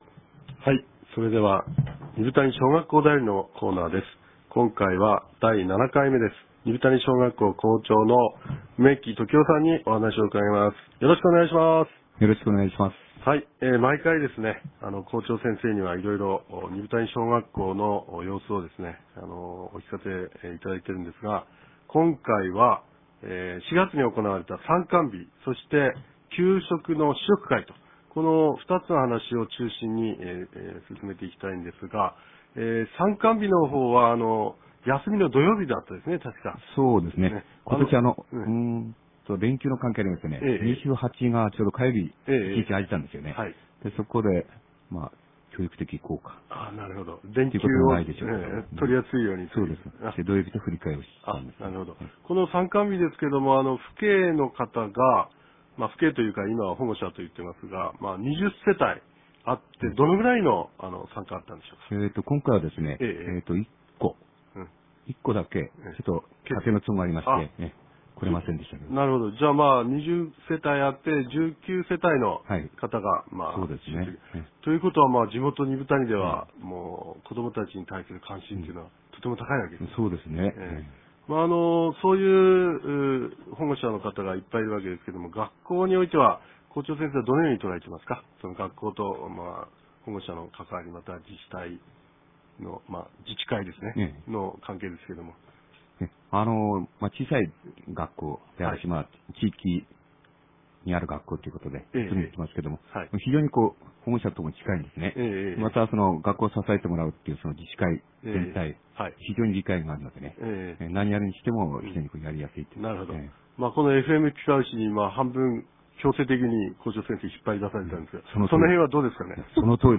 ■地元のフチへのインタビューコーナー：